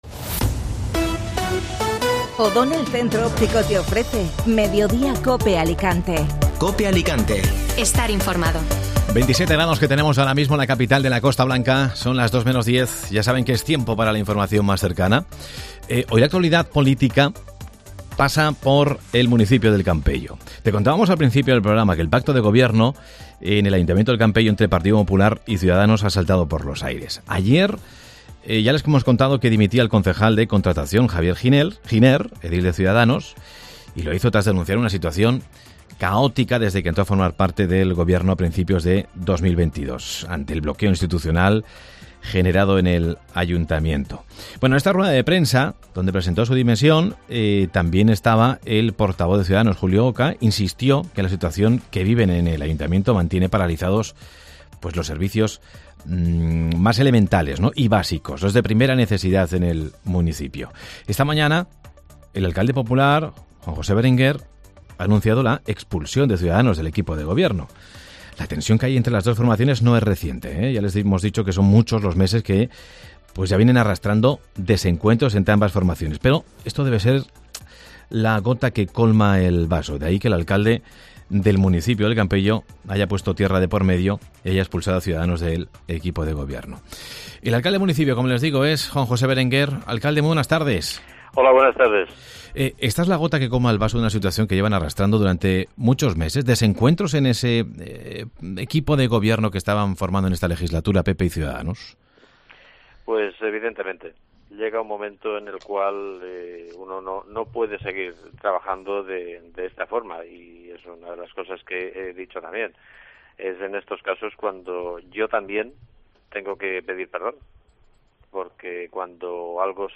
AUDIO: Escucha la entrevista con Juan José Berenguer, alcalde de El Campello